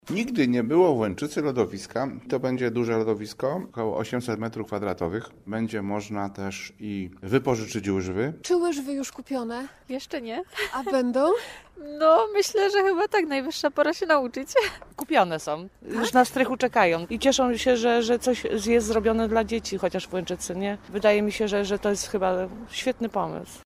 Mówi burmistrz Krzysztof Lipiński: Nazwa Plik Autor Przygotowanie lodowiska w Łęczycy audio (m4a) audio (oga) Lodowisko ma być gotowe do 15 grudnia.